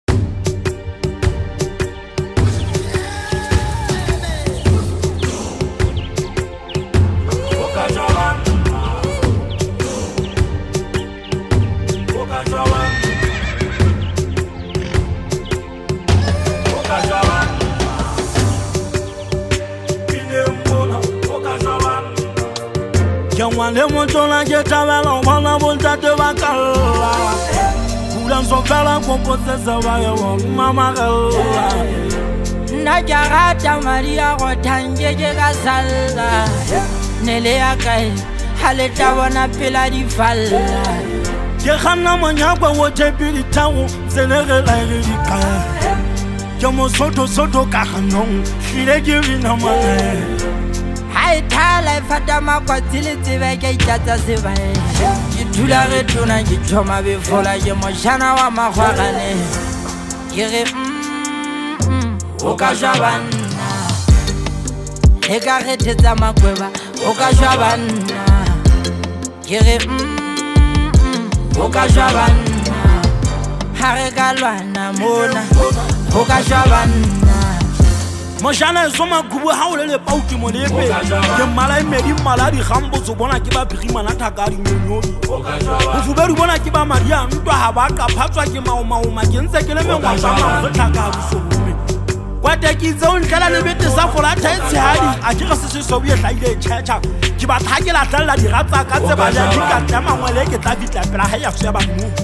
Maskandi, Amapiano